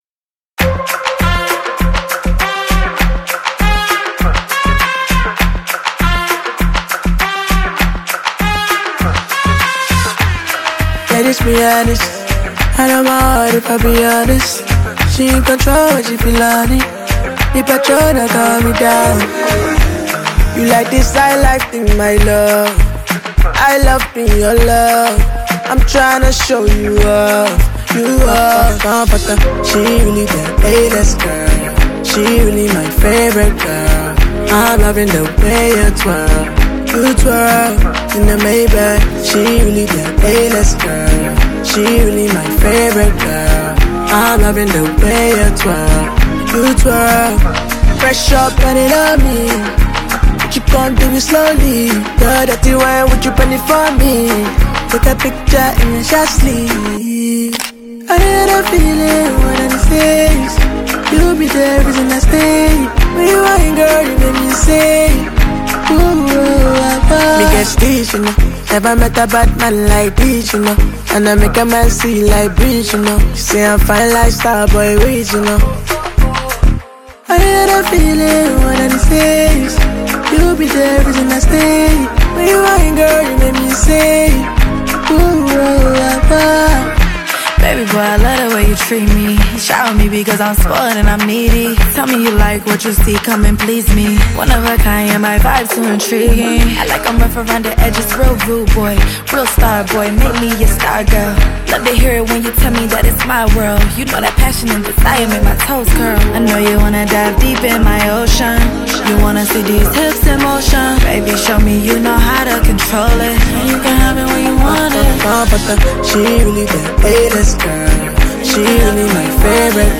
Talented Nigerian-born British rapper